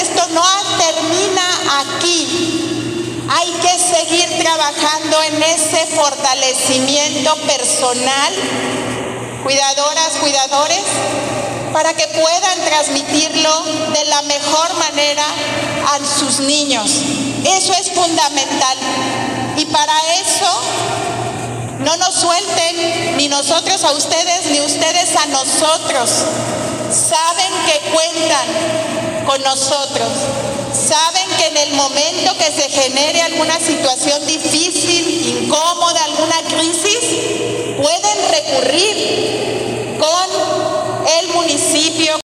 Lorena Alfaro, presidenta municipal